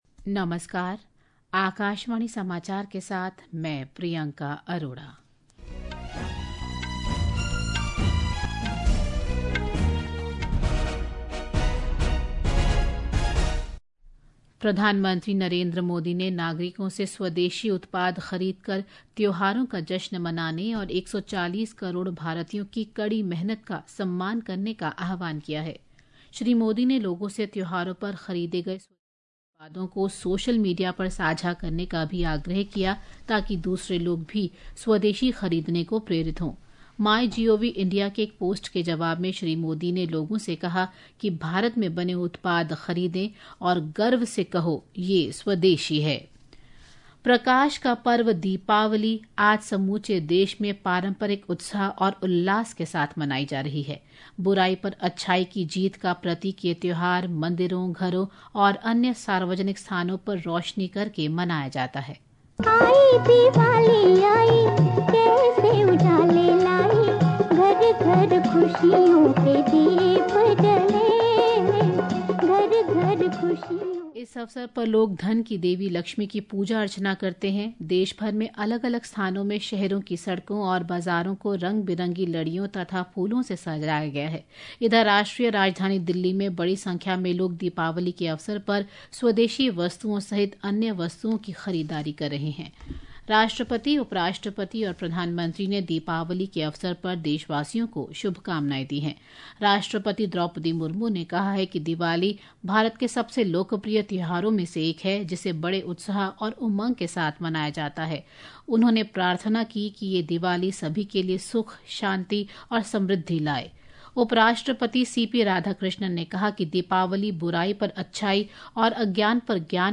राष्ट्रीय बुलेटिन
प्रति घंटा समाचार